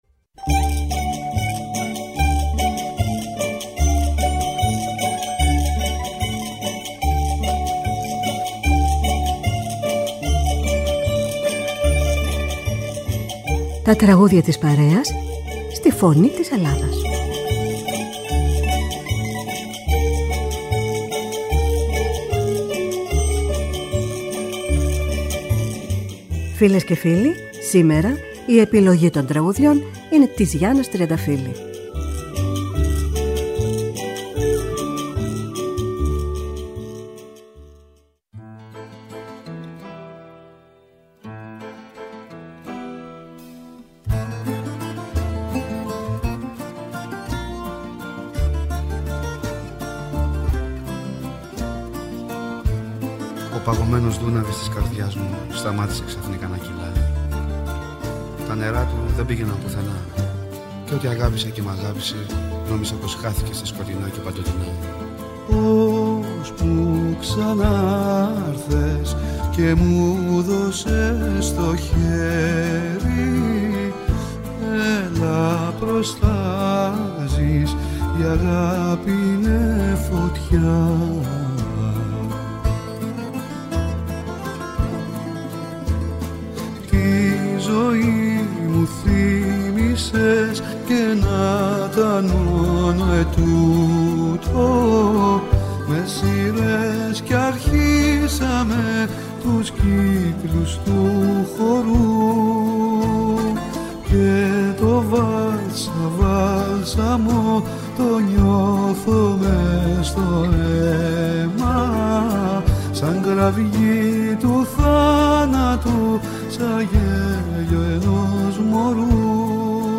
Με μουσικές από την Ελλάδα και τον κόσμο. Η ΦΩΝΗ ΤΗΣ ΕΛΛΑΔΑΣ Τα Τραγουδια της Παρεας στη Φωνη της Ελλαδας Μουσική Τραγουδια της παρεας ΦΩΝΗ ΤΗΣ ΕΛΛΑΔΑΣ